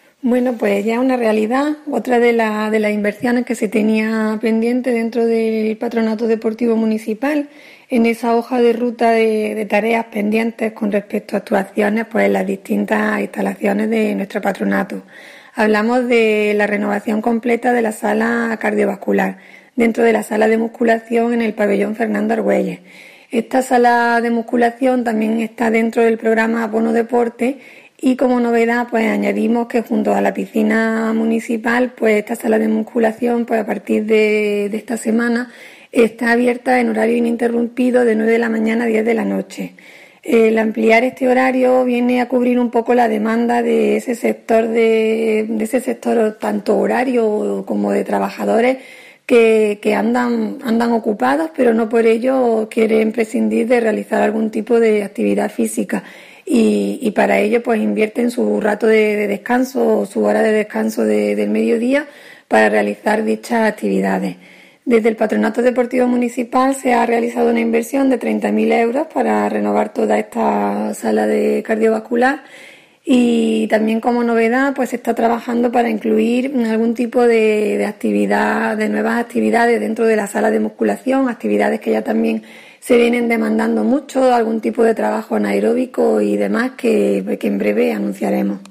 La concejal delegada de Deportes, Eugenia Galán, confirma la inversión de 30.000 euros por parte del Patronato Deportivo Municipal en la adquisición de 5 nuevas bicicletas estáticas, 4 cintas de correr y 1 elíptica con el objetivo de renovar la sala de ejercicio cardiovascular del gimnasio ubicado en la planta superior del pabellón polideportivo municipal Fernando Argüelles.
Cortes de voz E. Galán 606.78 kb Formato: mp3